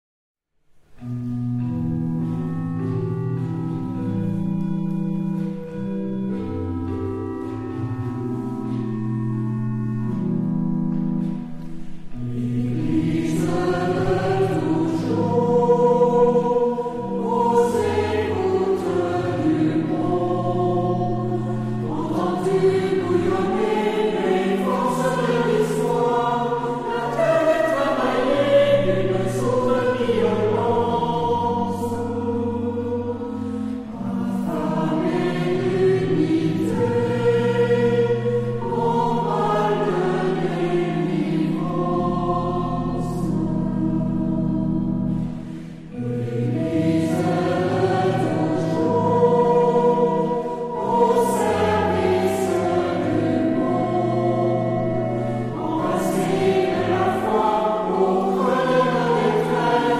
Genre-Style-Forme : Hymne (sacré) ; Sacré
Caractère de la pièce : solennel
Type de choeur : unisson
Instruments : Orgue (1)
Tonalité : mi mineur